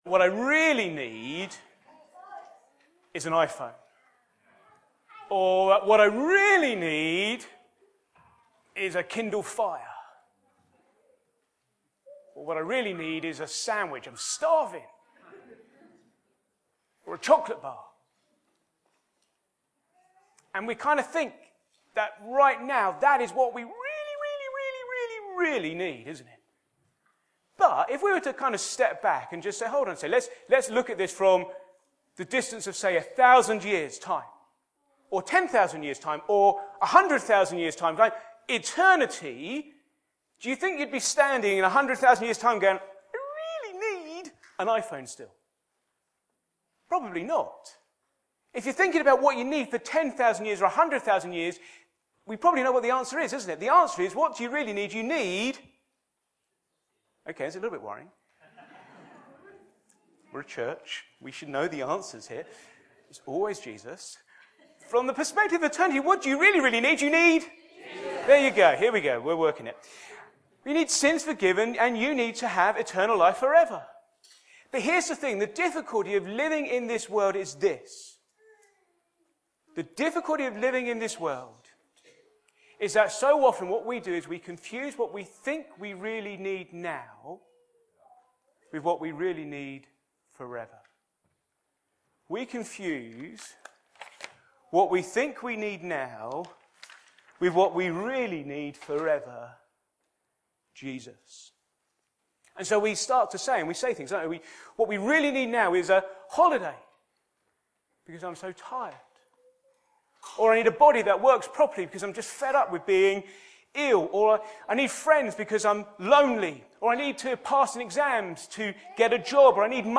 Back to Sermons John 6:1 to 71